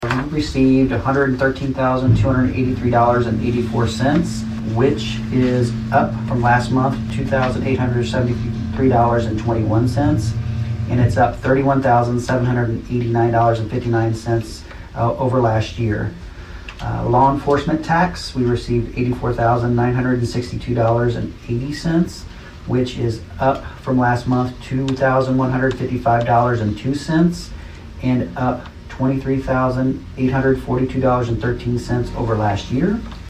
Saline County Treasurer Jared Brewer delivered the sales-tax report for March during the meeting of the county commission on Thursday, March 11.